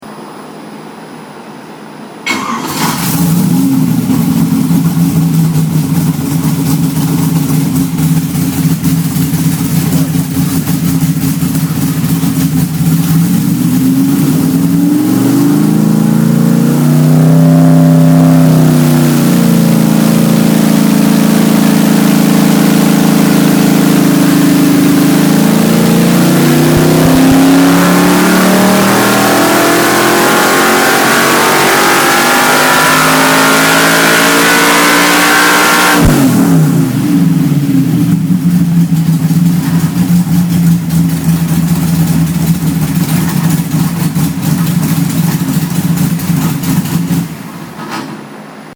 Here are links to a couple of sound clips of a iron headed drag race Y engine making in excess of 500 HP through the mufflers.
500+ Horsepower dyno pull 3
This is an iron headed racing Y with more compression ratio than allowed for the EMC competition and more camshaft than I have in the EMC entry.